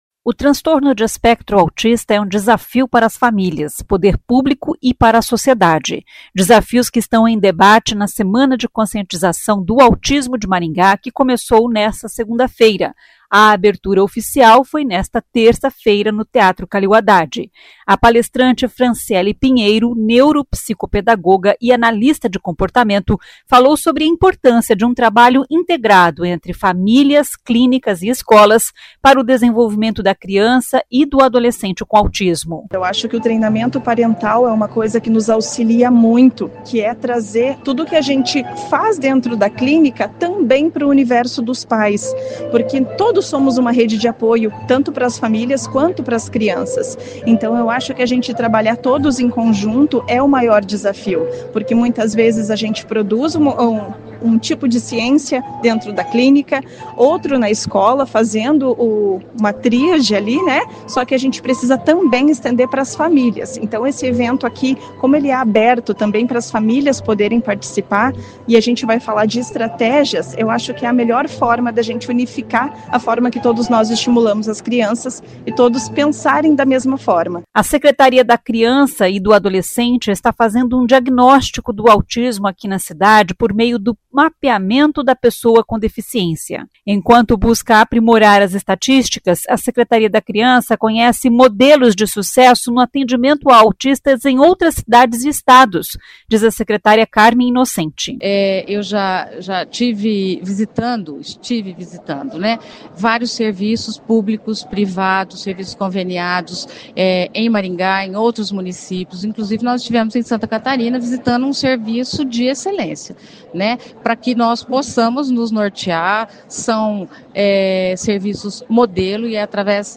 A abertura oficial foi nesta terça-feira(1º) no Teatro Calil Haddad.
O prefeito Sílvio Barros disse, na cerimônia de abertura da semana, que muitos professores de apoio estão pedindo demissão, por isso a contratação de novos profissionais está sendo mais rigorosa.